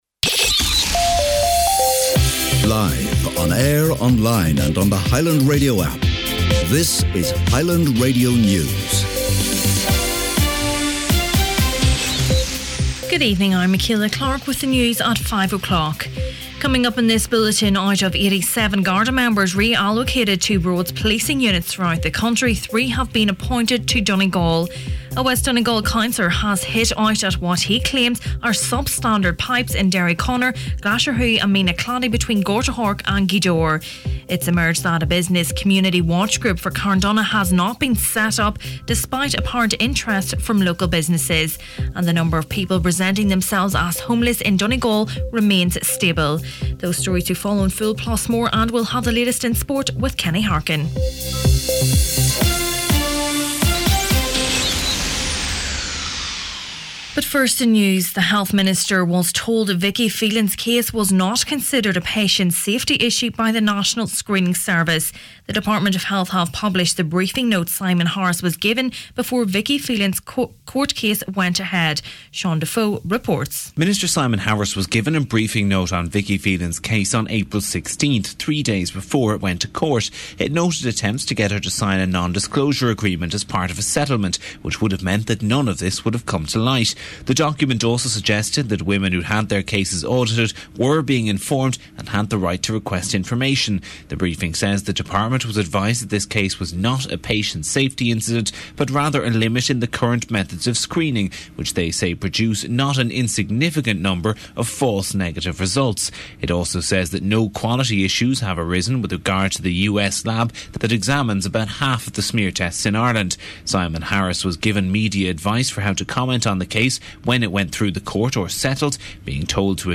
Main Evening News, Sport and Obituaries Tuesday 1st May